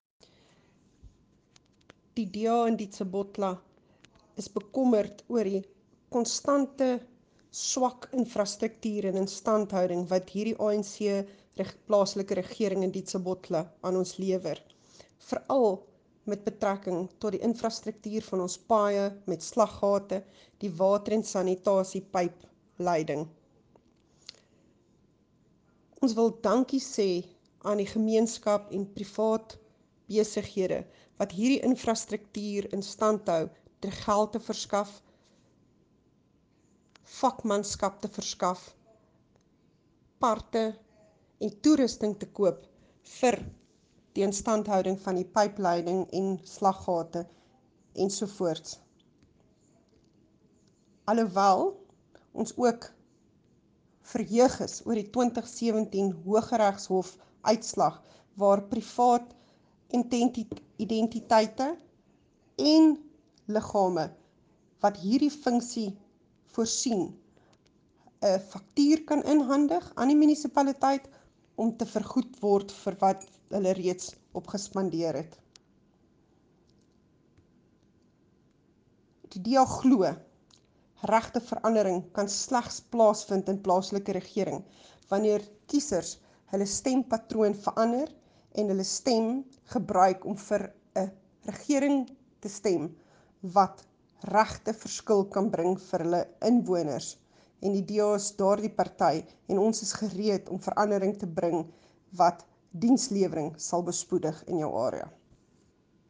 Opmerking aan redakteurs: vind asseblief die aangehegte klankgrepe van die DA-kiesafdelingshoof, Jacqueline Theologo, MPL, in